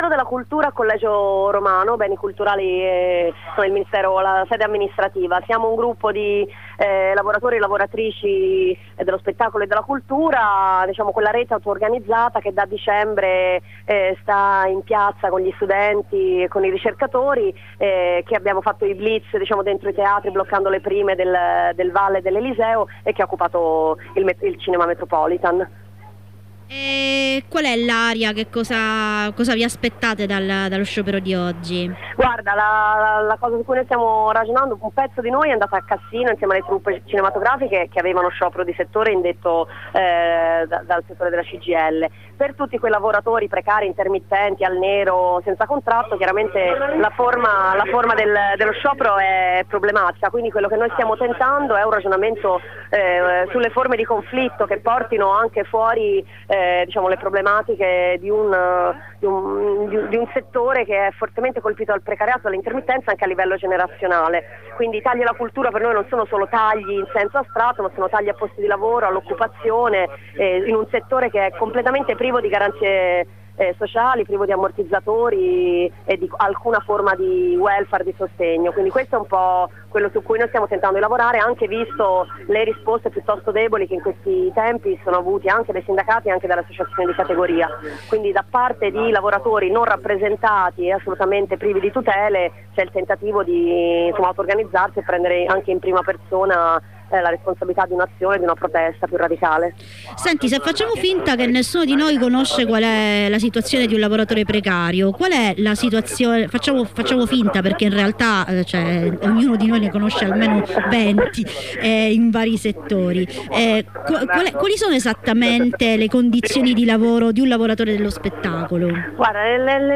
Continua la mobilitazione dei lavoratori dello spettacolo che la scorsa settimana hanno occupato il cinema Metropolitan e che scelgono la giornata dello sciopero generale, per scendere in presidio davanti al Ministero dei beni e delle attività culturali.  Durante la corrispondenza, una delegazione è stata ricevuta; restiamo in attesa di informazioni.